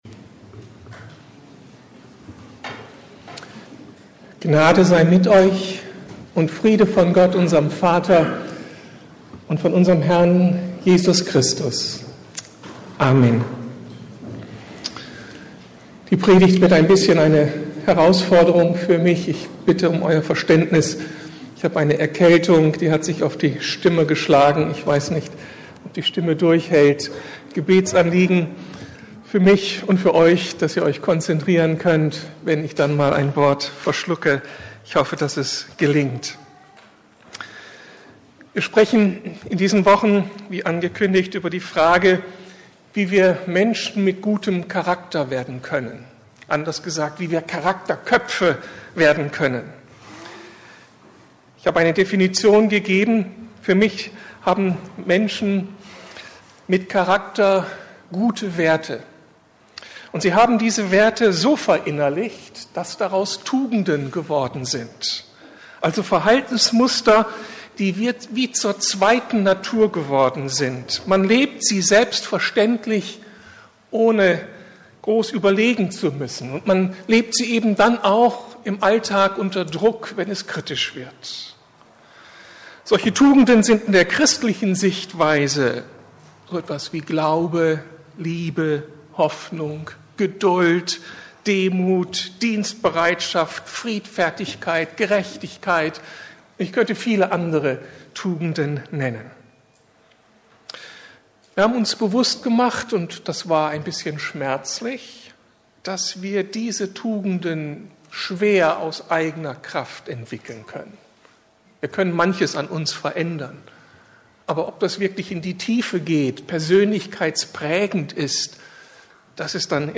Hilfen auf dem Weg zum „Charakterkopf“ 2/3 ~ Predigten der LUKAS GEMEINDE Podcast